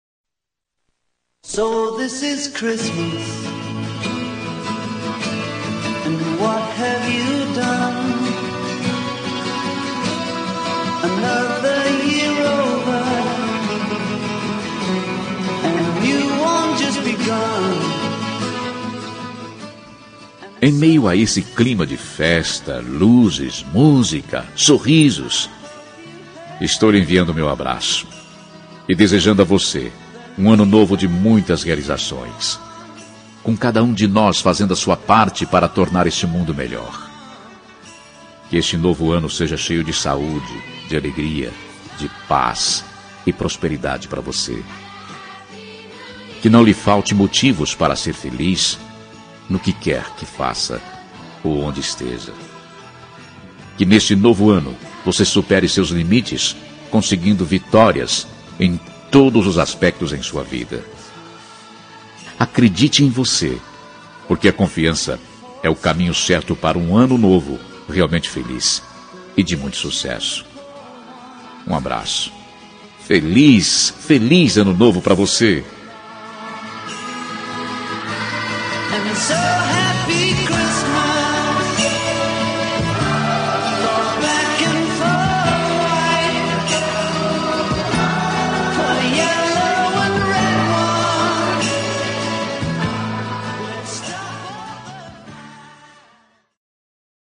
Ano Novo – Pessoa Especial – Voz Masculina – Cód: 6410 – Linda
6410-ano-no-masc-neutra-linda.m4a